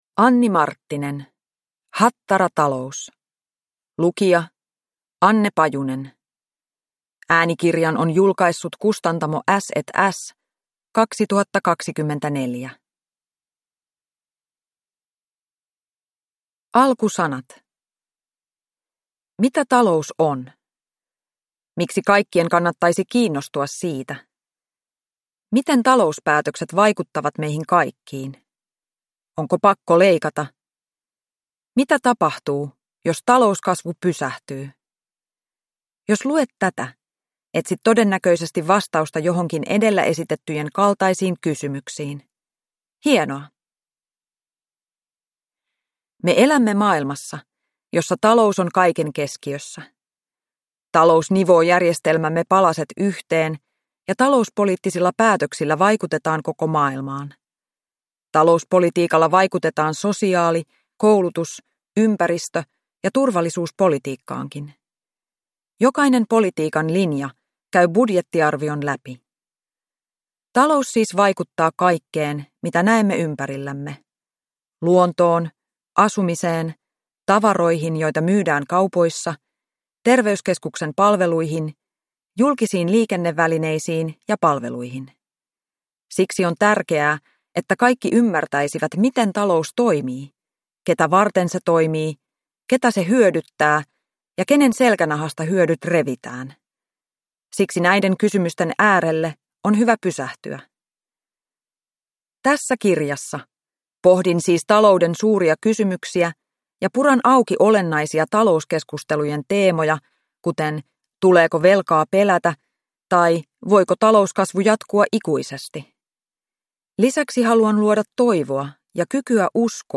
Hattaratalous – Ljudbok